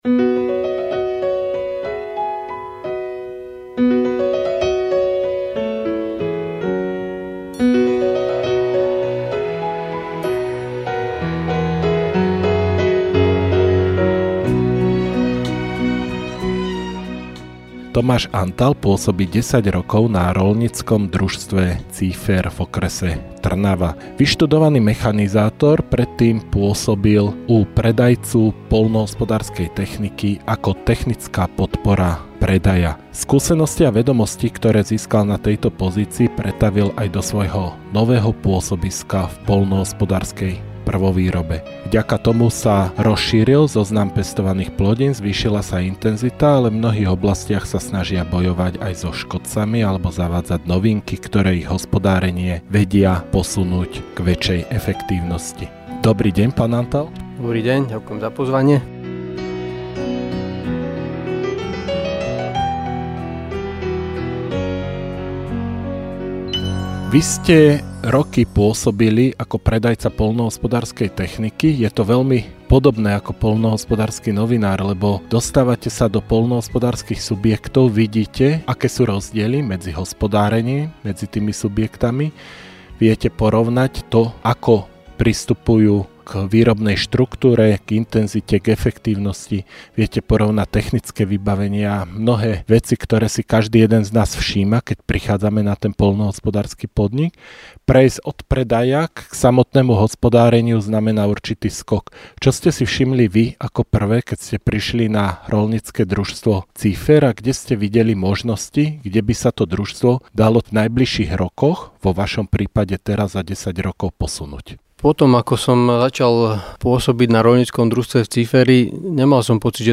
V aktuálnom rozhovore hovoríme o dôvodoch širokej štruktúry ich rastlinnej výroby, vlahe – ktorá sa stáva čoraz častejším problémom, zmene prístupu k hnojeniu či prečo práve v tomto roku začínajú s pestovaním cukrovej repy. Najväčšiu časť rozhovoru ale venujeme ich riešeniu situácie s hrabošmi a využitiu dronov v poľnohospodárstve.